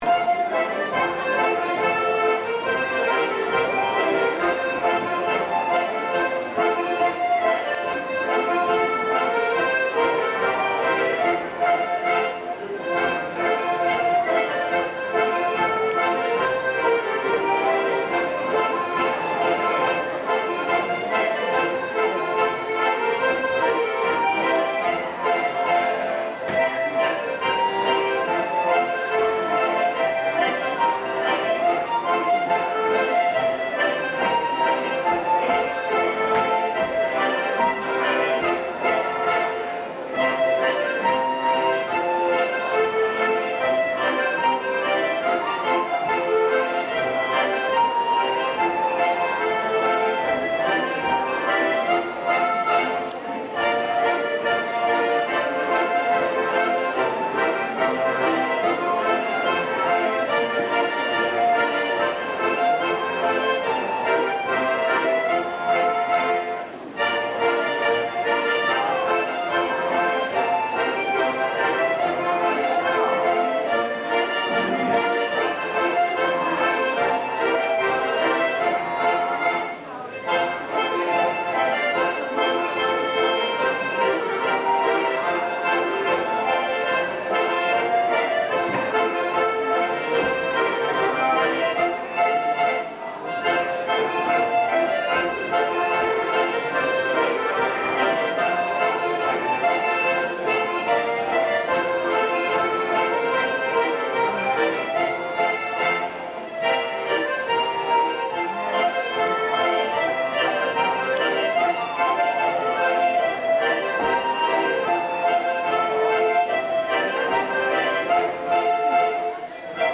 Deze Zwitserse polka (oftwel een scottisch)
�Dr M�tsche Geischt� �Dr M�tsche Geischt� �Dr M�tsche Geischt� �Dr M�tsche Geischt� De deun, zoals gespeeld tijdens de presentatie van de workshop op zaterdag 19 mei 2007 Pflanzpl�tz